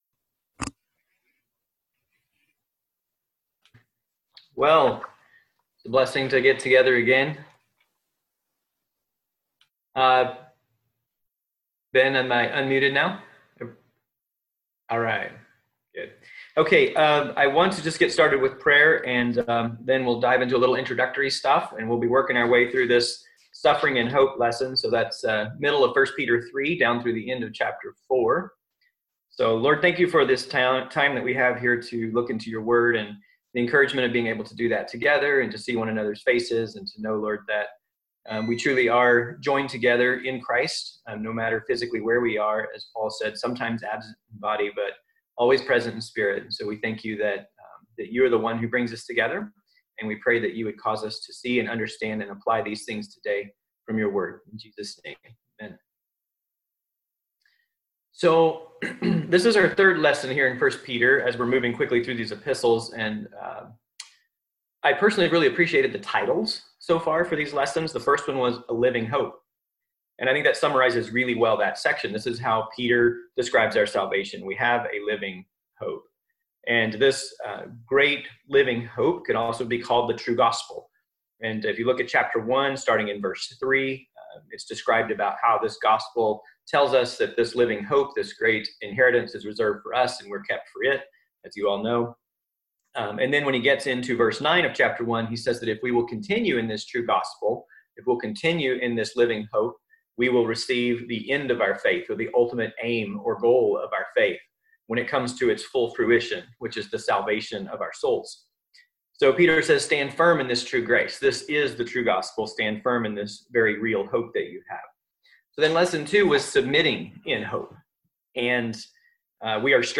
1 Peter 3:13-4:19 Service Type: Sunday School « He Knows How to Judge